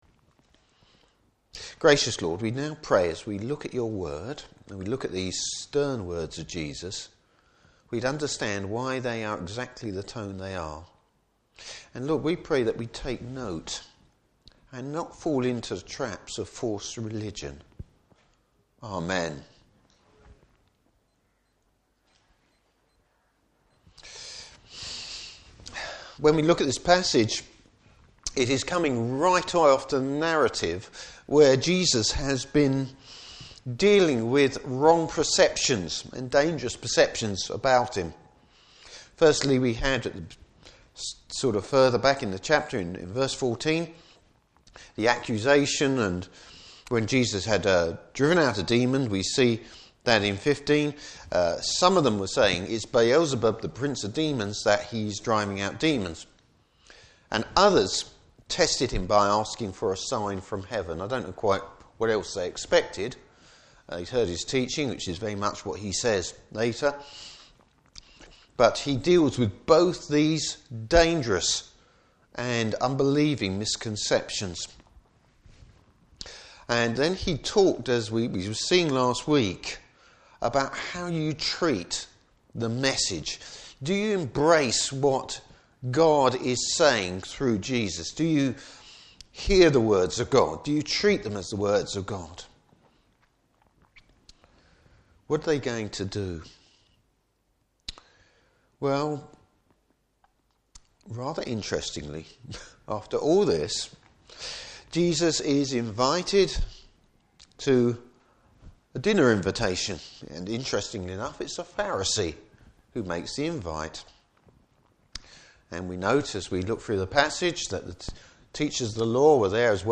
Service Type: Morning Service Bible Text: Luke 11:37-54.